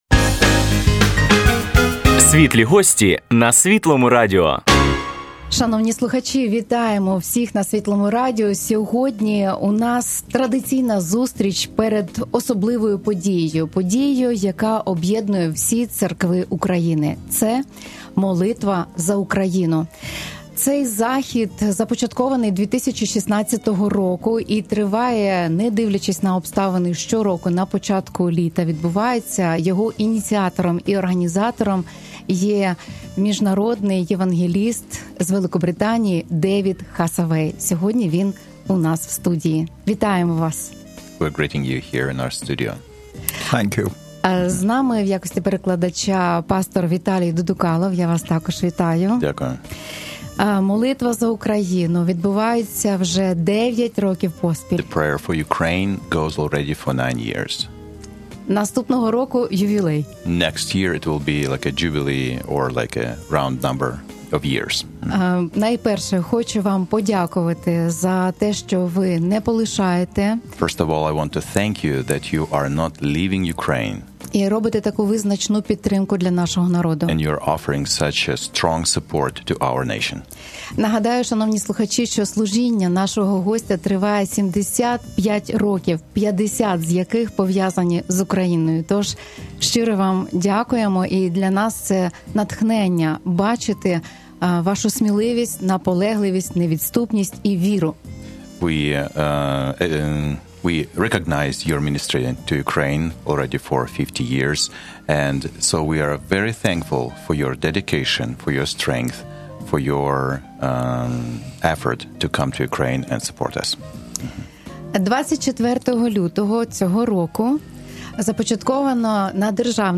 У цій програмі ви почуєте унікальну розмову напередодні особливої події, що об'єднує церкви України – щорічної Молитви за Україну.